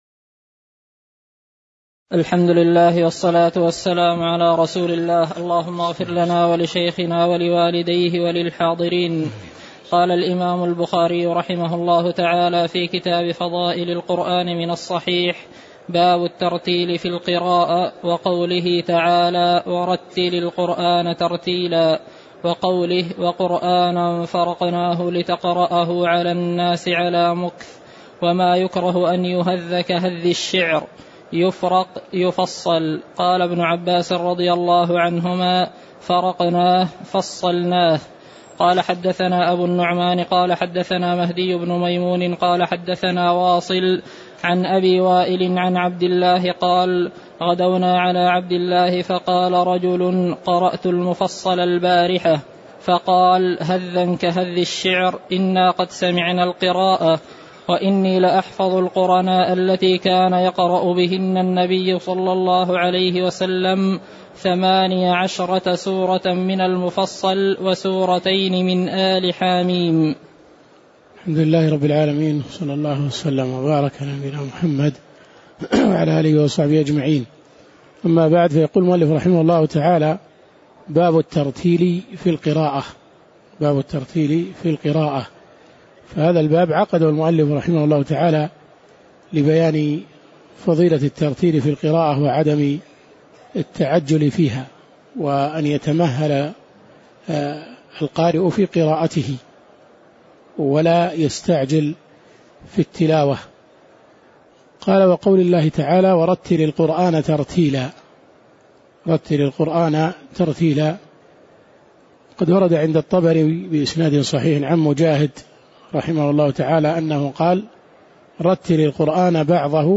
تاريخ النشر ٢٠ رمضان ١٤٣٩ هـ المكان: المسجد النبوي الشيخ